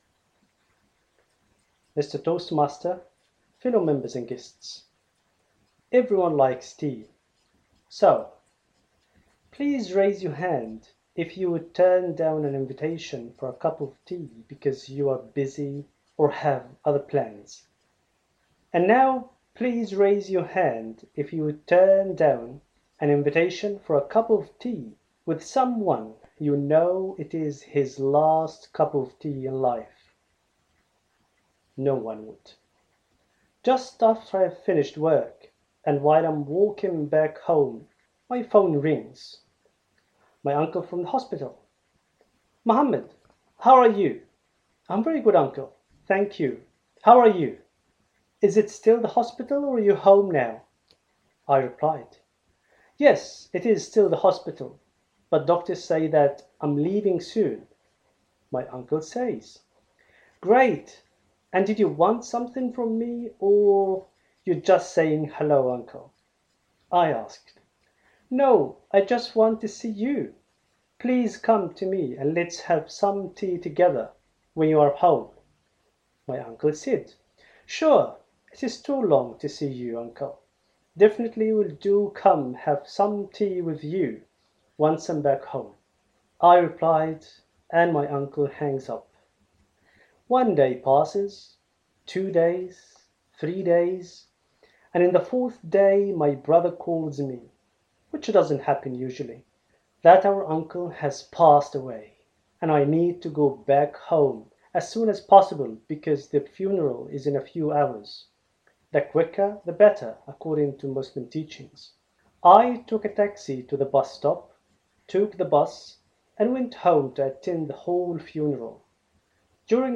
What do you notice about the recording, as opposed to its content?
First Toastmaster speech; Ice Breaker.